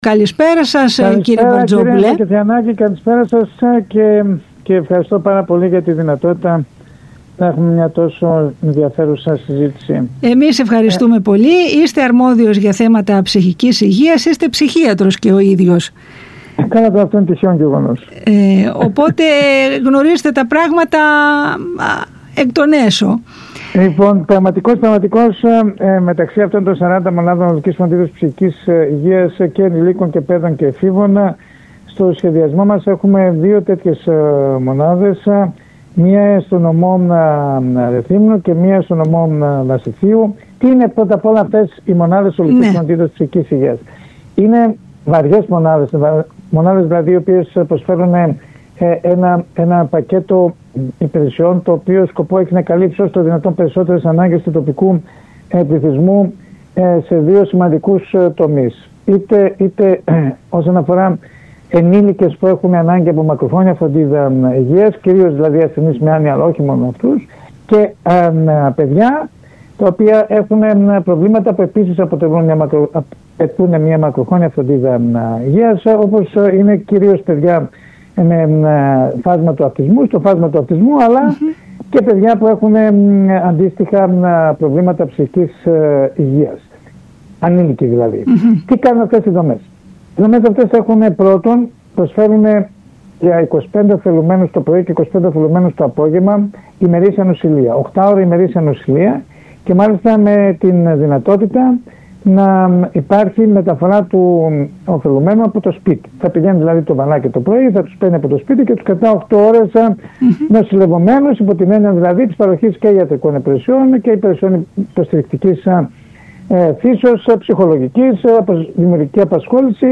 Στον ΣΚΑΪ Κρήτης 92.1 ο Υφυπουργός Υγείας αρμόδιος για θέματα ψυχικής υγείας Δημήτρης Βαρτζόπουλος